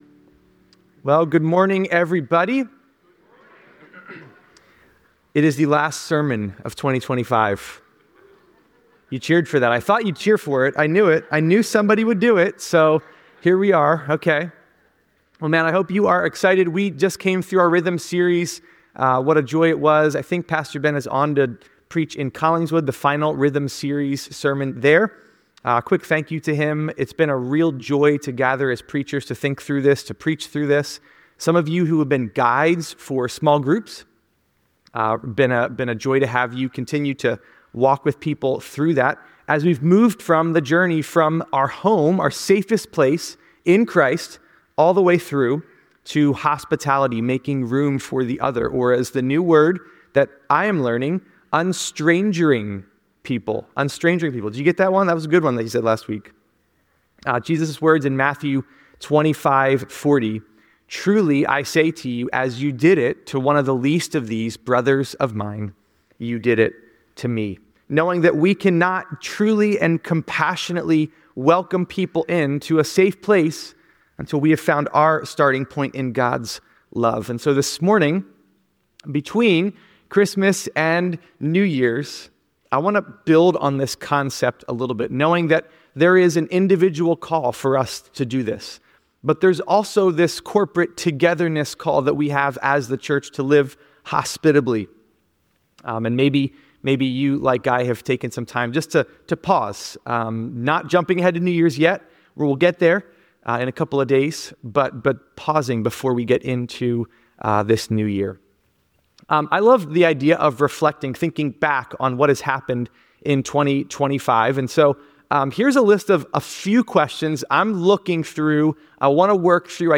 delivers the last sermon of 2025